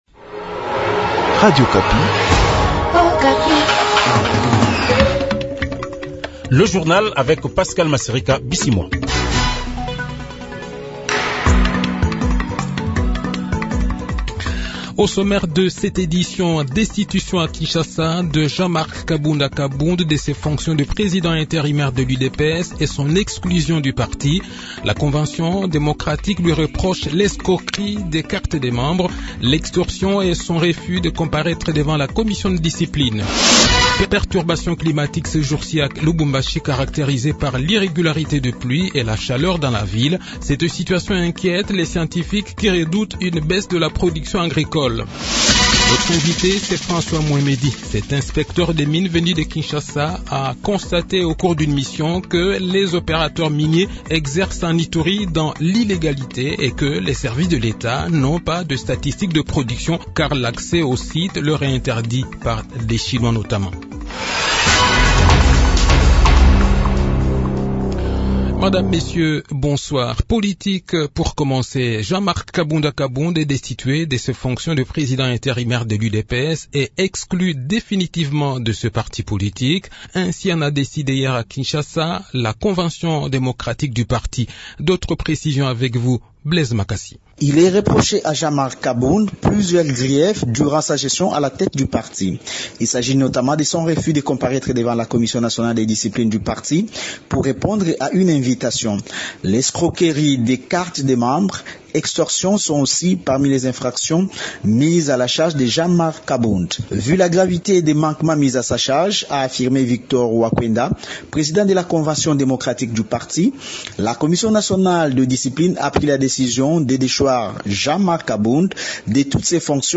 Le journal de 18 h, 30 janvier 2022